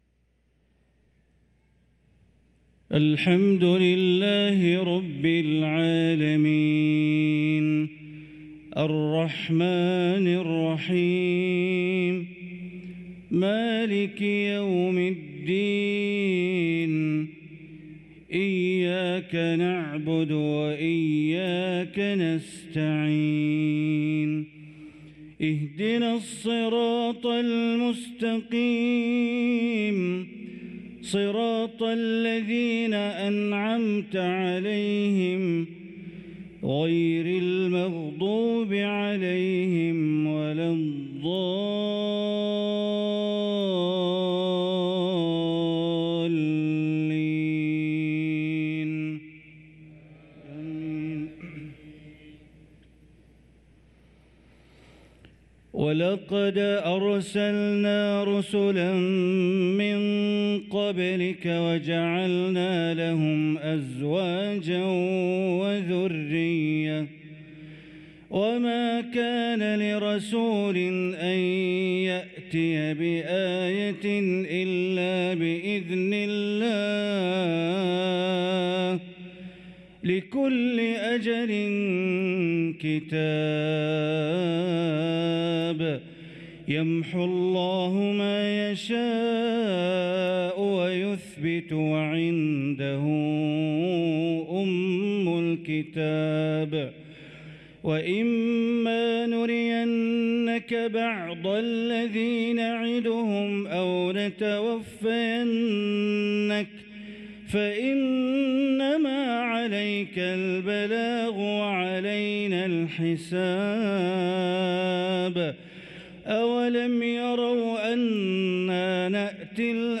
صلاة العشاء للقارئ بندر بليلة 13 ربيع الآخر 1445 هـ